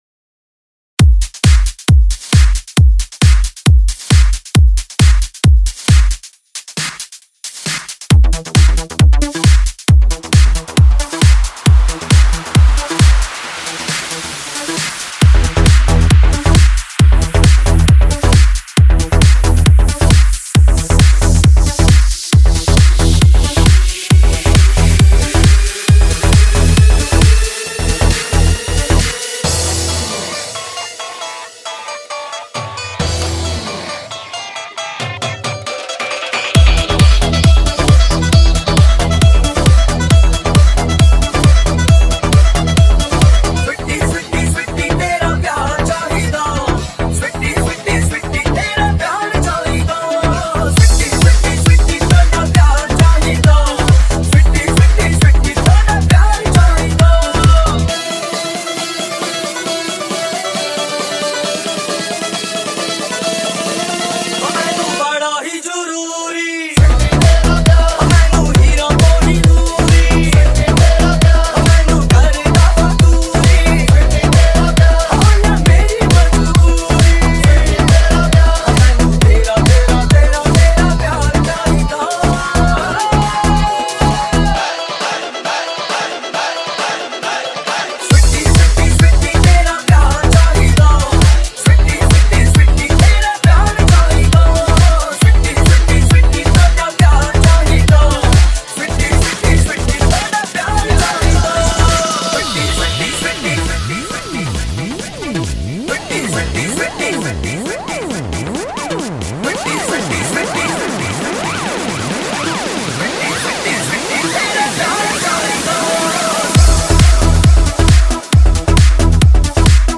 DJ Remix Mp3 Songs